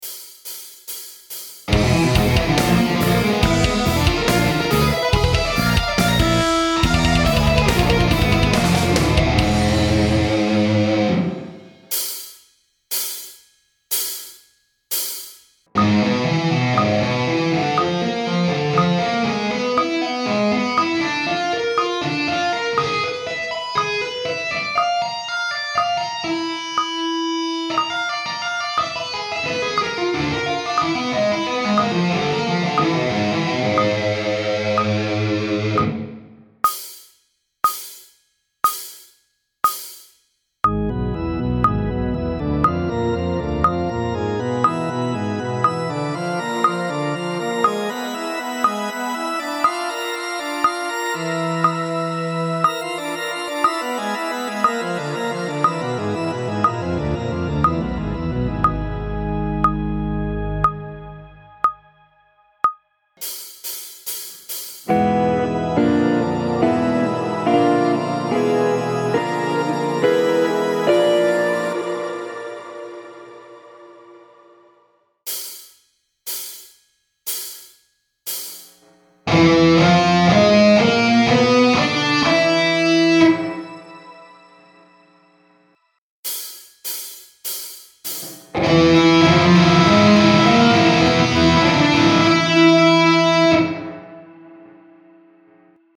it's a finger tapping lick